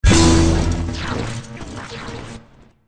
playerdies_6.ogg